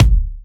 • Thumpy Bass Drum One Shot D Key 28.wav
Royality free kick sample tuned to the D note. Loudest frequency: 489Hz
thumpy-bass-drum-one-shot-d-key-28-RqZ.wav